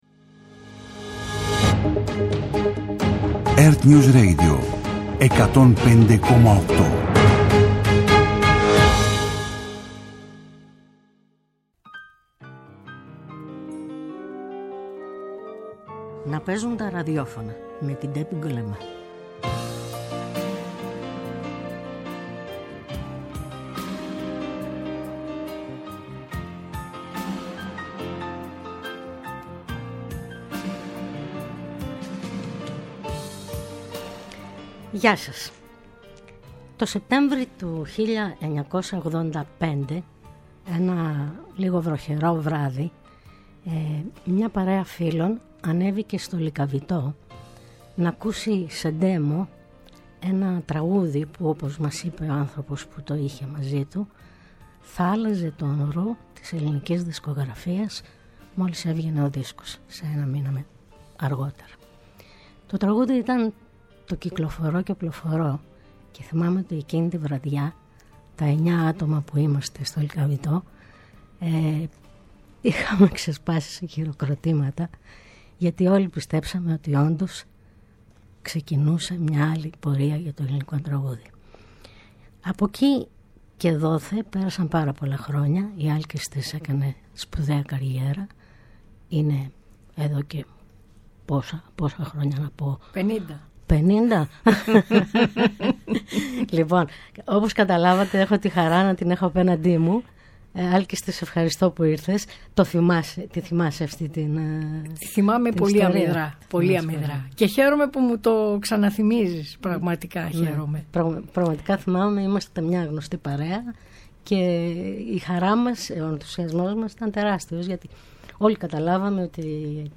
-Καλεσμένη μια από τις σημαντικότερες ερμηνεύτριες της ελληνικής μουσικής σκηνής, η Άλκηστις Πρωτοψάλτη
Η εκπομπή “Να παίζουν τα ραδιόφωνα” κάθε Σάββατο στη 1 το μεσημέρι φιλοξενεί στο studio ανθρώπους της Τέχνης -και όχι μόνο- σε ενδιαφέρουσες συζητήσεις με εξολογητική και χαλαρή διάθεση.